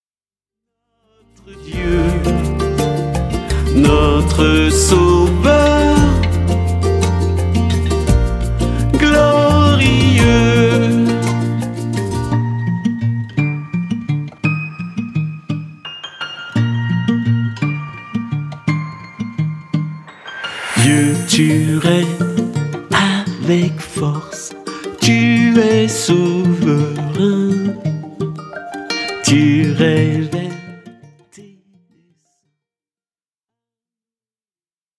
Une pop-Louange actuelle à la fois profonde et dansante